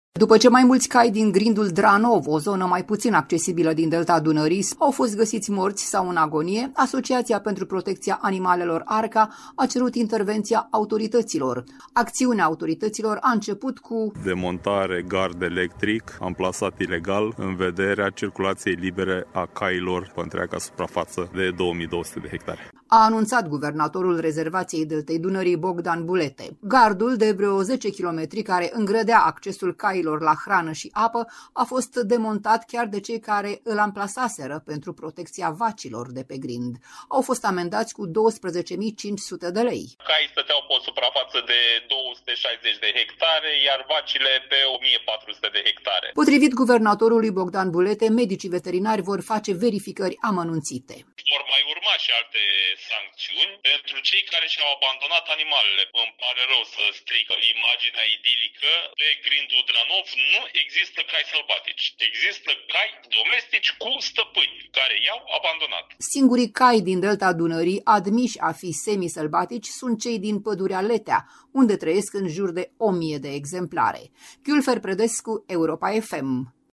„Caii stăteau pe o suprafață de 260 de hectare, iar vacile, pe 1.400 de hectare”, a declarat guvernatorul Bogdan Bulete.